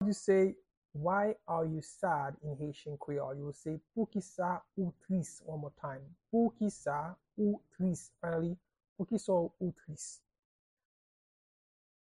Pronunciation and Transcript:
How-to-say-Why-are-you-sad-in-Haitian-Creole-–-Poukisa-ou-tris-pronunciation-by-a-Haitian-Creole-teacher.mp3